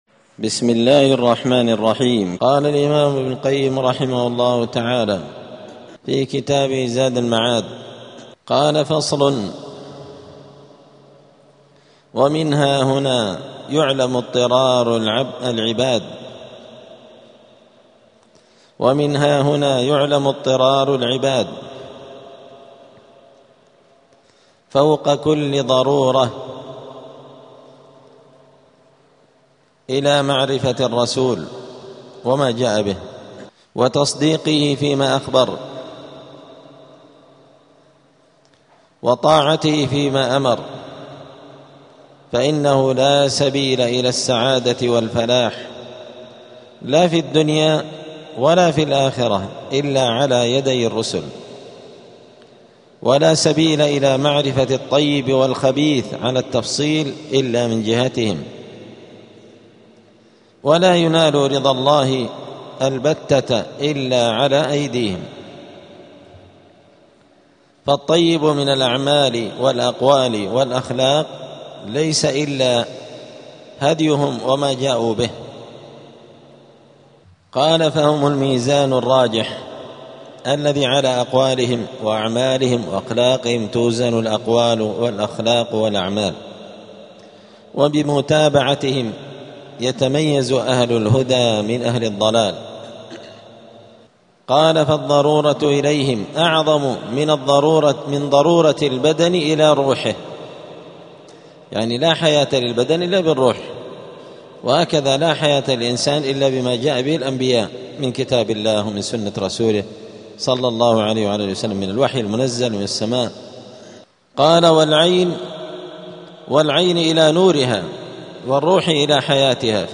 الجمعة 13 رجب 1447 هــــ | الدروس، دروس الآداب، زاد المعاد في هدي خير العباد لابن القيم رحمه الله | شارك بتعليقك | 2 المشاهدات
دار الحديث السلفية بمسجد الفرقان قشن المهرة اليمن